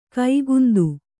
♪ kaigundu